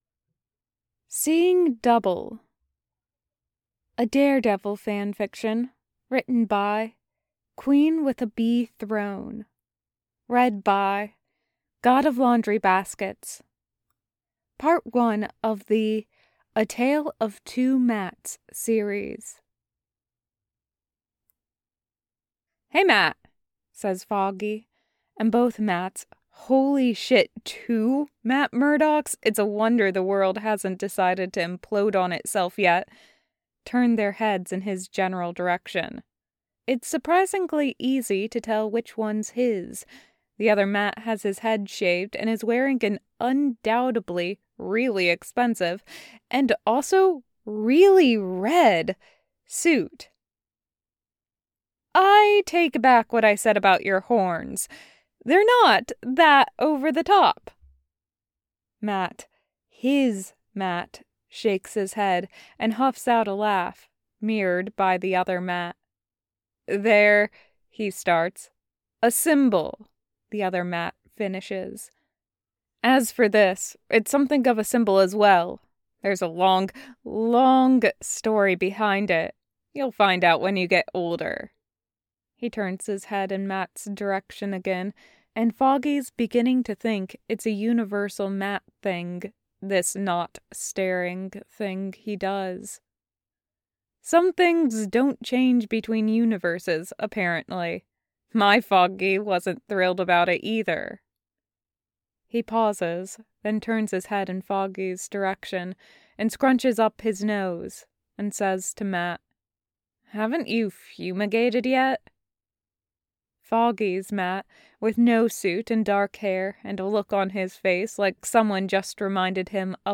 [Podfic] seeing double